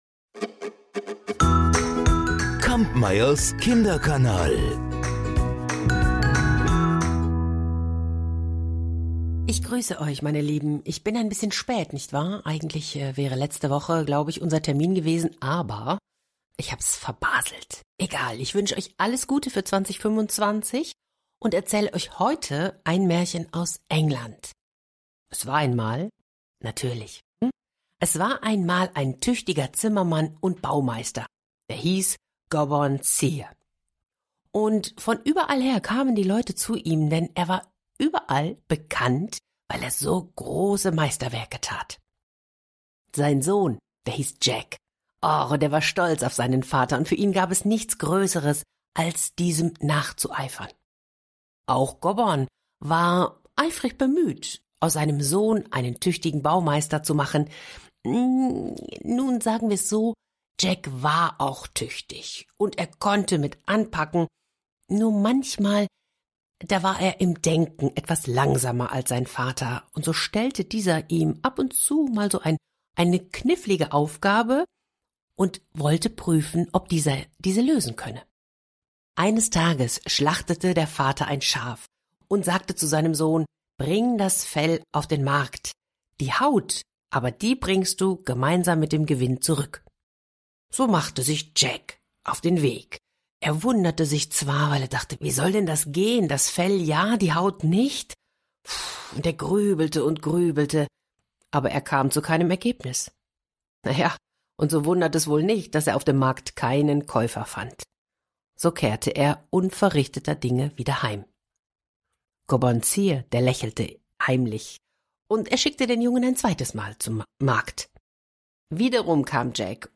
Gutenachtgeschichten
Storytelling